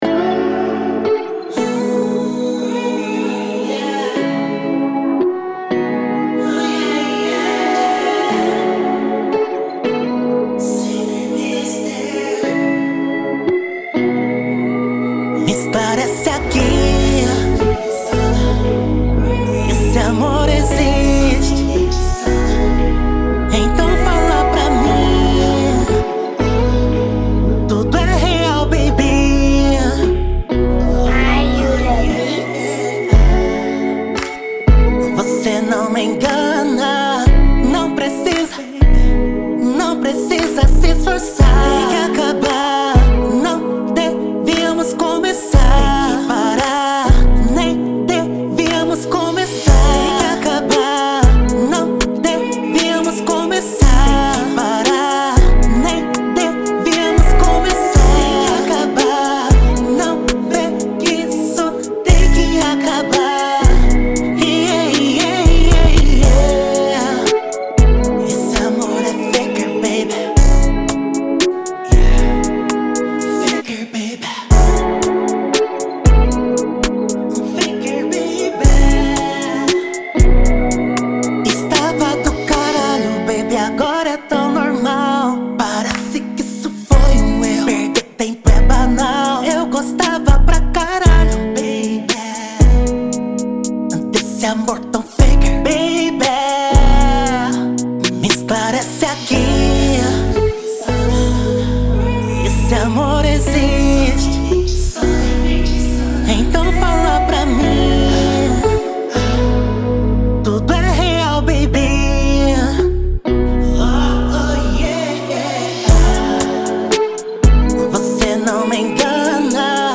EstiloR&B